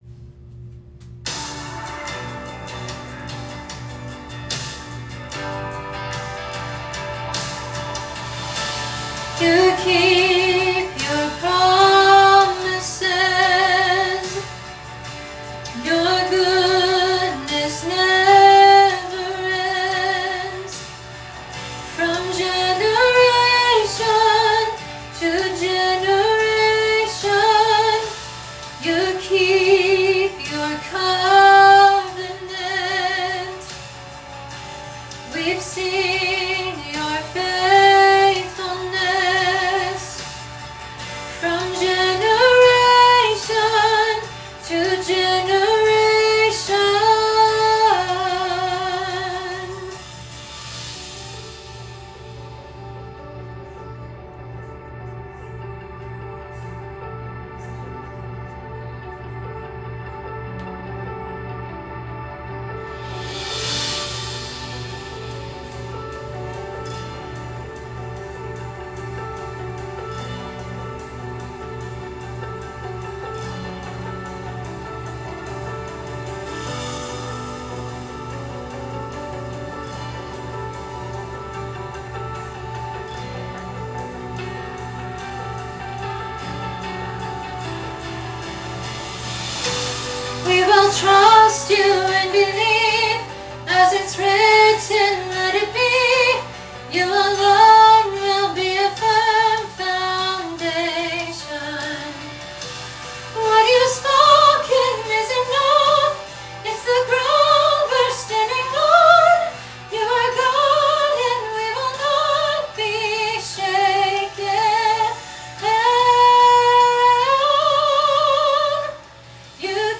Lyric Sheet, Sheet Music & Vocal Rehearsal Tracks
The audio quality may be a bit raw but it will be sufficient for you to practice on your own.
Female Soprano Track